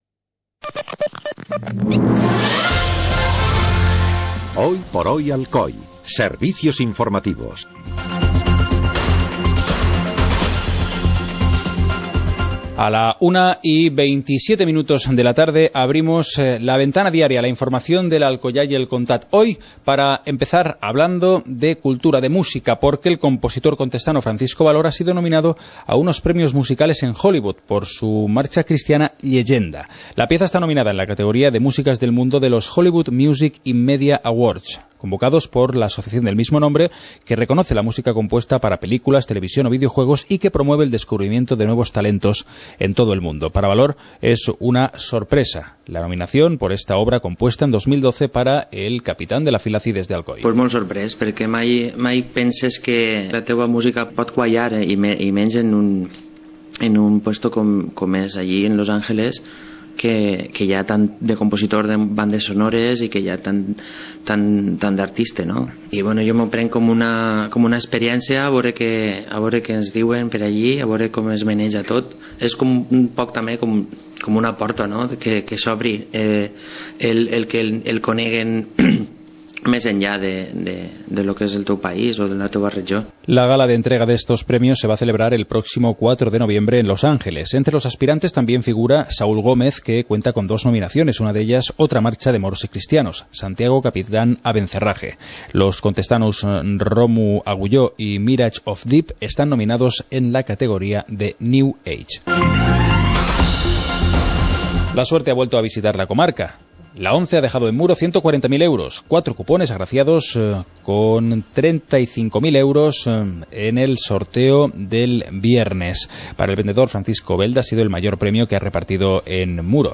Informativo comarcal - lunes, 18 de agosto de 2014